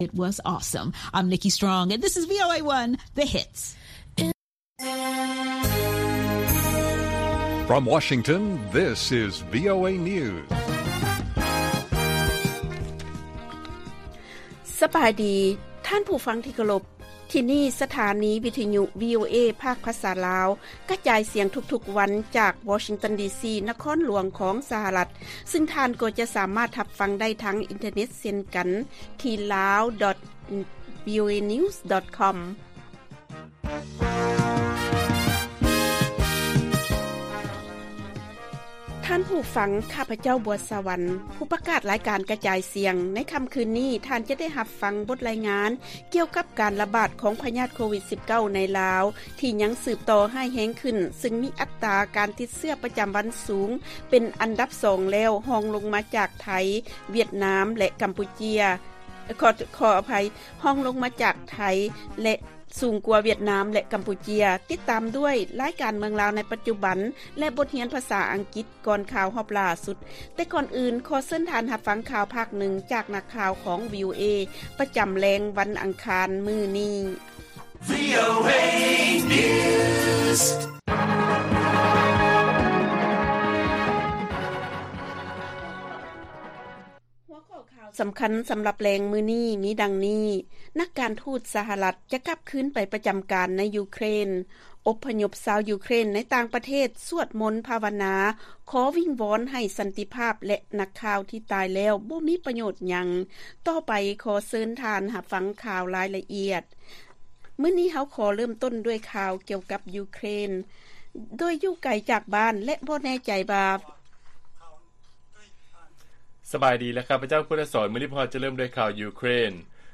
ວີໂອເອພາກພາສາລາວ ກະຈາຍສຽງທຸກໆວັນ, ຫົວຂໍ້ຂ່າວສໍາຄັນໃນມື້ນີ້ມີ: 1. ນັກການທູດສະຫະລັດ ຈະກັບຄືນໄປປະຈໍາການຢູ່ໃນ ຢູເຄຣນ, 2. ຊາວຢູເຄຣນ ທີ່ພັດພາກບ້ານເກີດເມືອງນອນ ຍ້ອນສົງຄາມ ໄດ້ເຕົ້າໂຮມກັນຢູ່ໂບດແຫ່ງຕ່າງໆ ໃນທົ່ວຢູໂຣບຕາເວັນອອກ, ແລະ 3. ນັກຂ່າວທີ່ເສຍຊີວິດ ແມ່ນມີປະໂຫຍພຽງໜ້ອຍດຽວ.